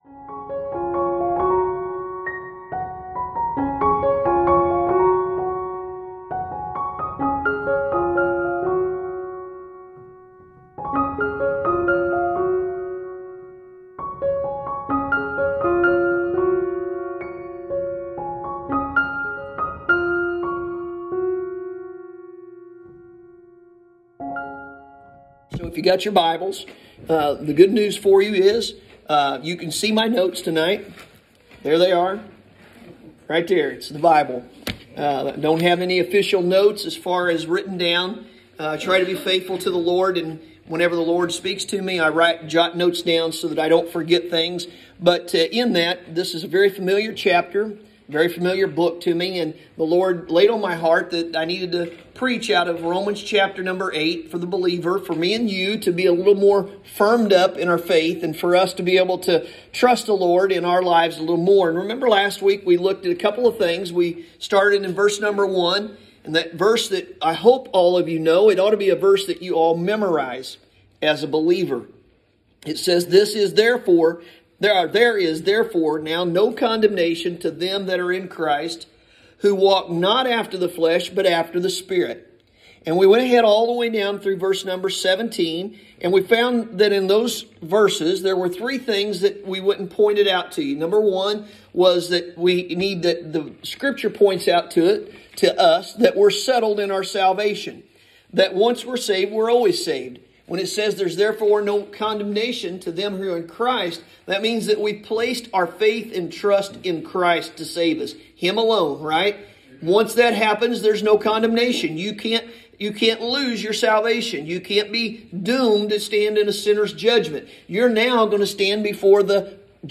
Sunday Evening – January 11, 2021